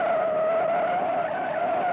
KART_Skidding_On_Asphalt.ogg